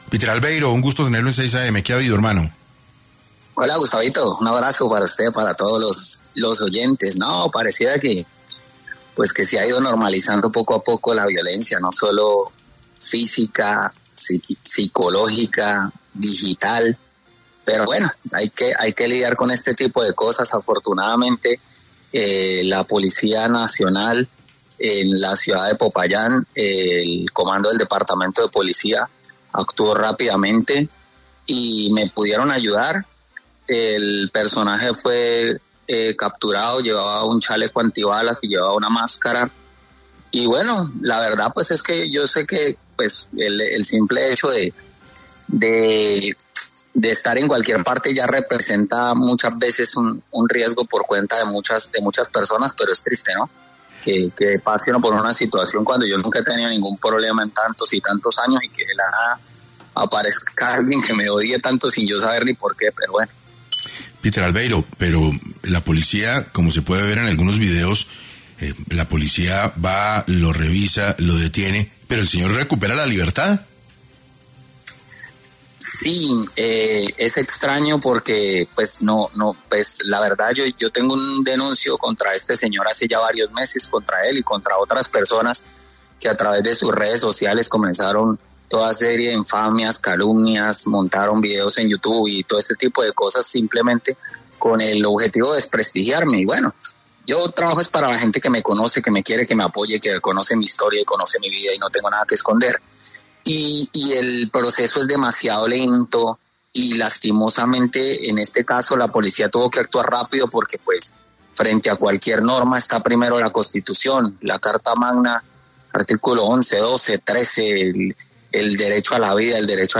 El humorista estuvo en 6AM para profundizar sobre los mensajes intimidatorios y la amenaza de muerte que recibió.